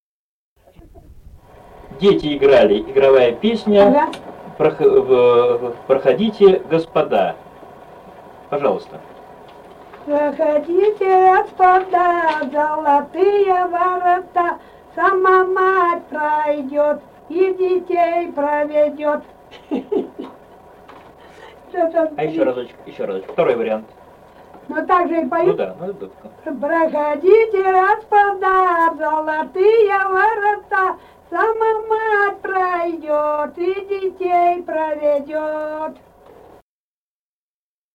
«Проходите, господа» игровая на предсвадебных вечёрках
Республика Казахстан, Восточно-Казахстанская обл., Катон-Карагайский р-н, с. Урыль (казаки), июль 1978.